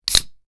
can_open4.ogg